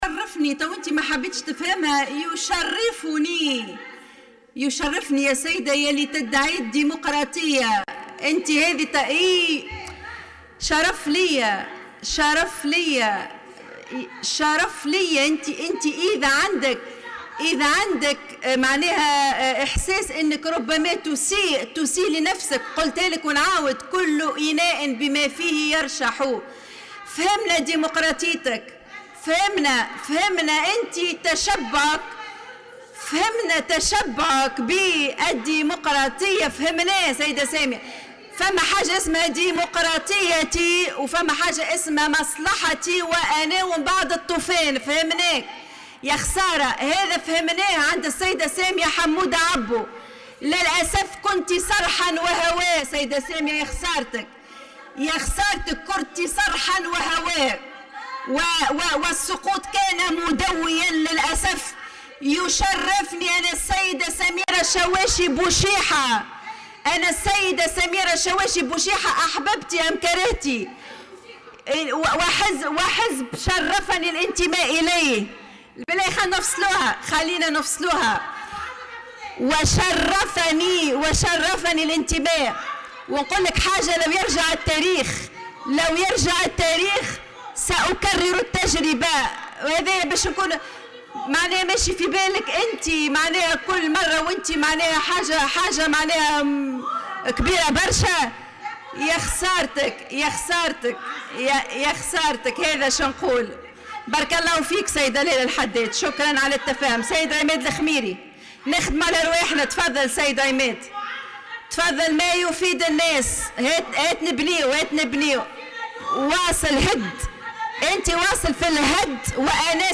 سادت ظهر اليوم حالة من الاحتقان والتشنج تحت قبة البرلمان بسبب مشادة كلامية بين النائب الأوّل لرئيس مجلس نواب الشعب، سميرة الشواشي والنائب عن التيار الديمقراطي، سامية عبو.